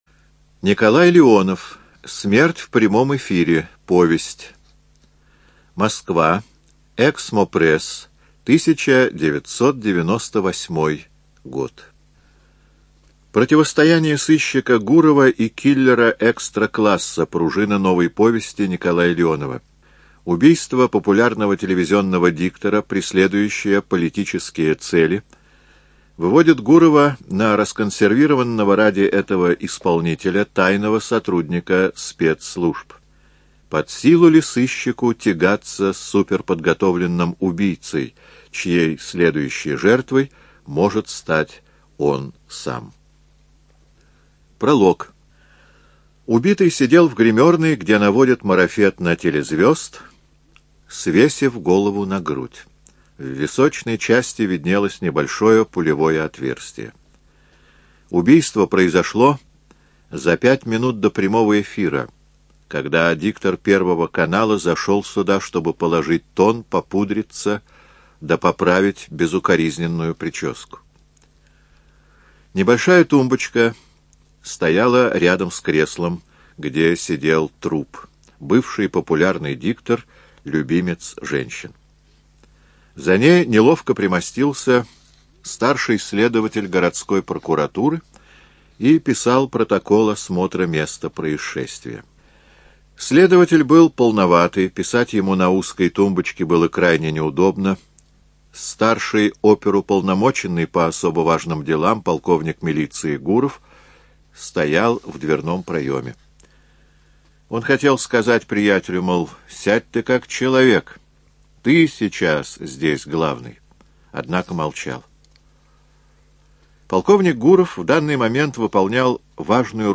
Студия звукозаписиЛогосвос